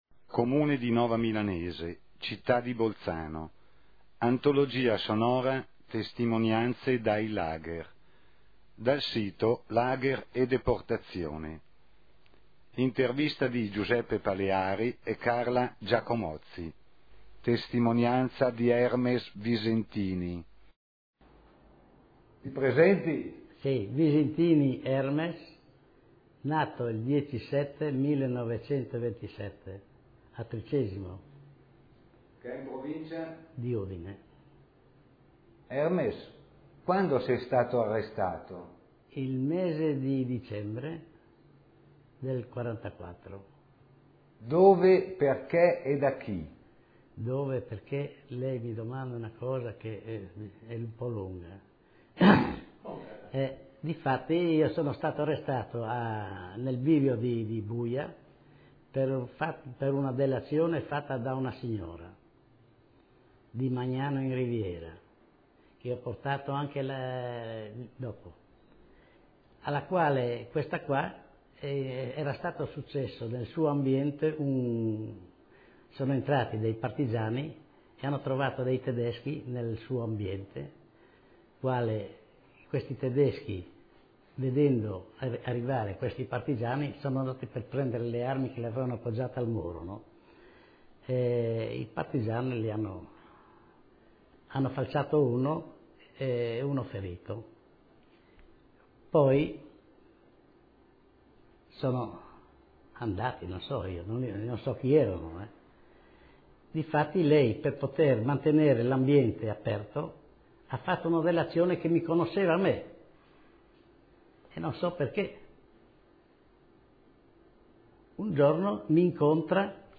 Intervista del